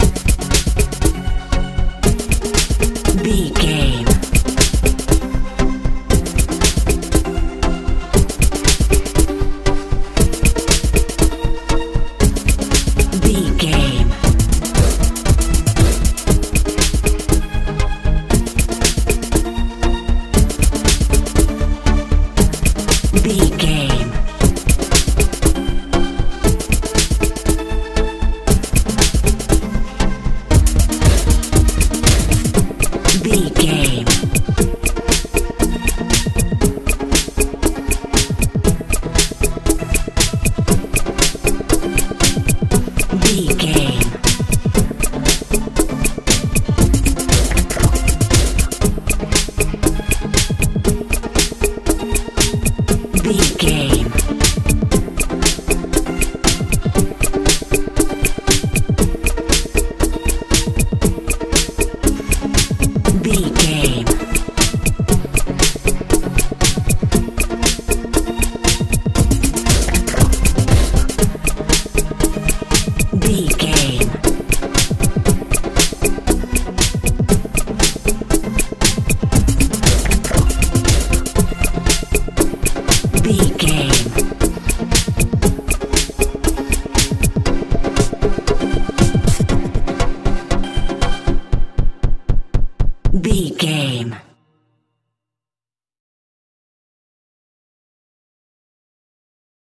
techno house feel
Ionian/Major
F♯
magical
mystical
synthesiser
bass guitar
drums
80s
90s